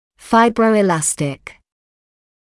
[ˌfaɪbrəuɪ’læstɪk][ˌфайброуи’лэстик]фиброэластичный